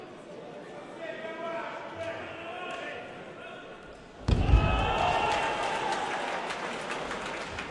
描述：体育摔跤欢呼掌声体育场
Tag: 体育场 掌声 摔跤 体育 欢呼声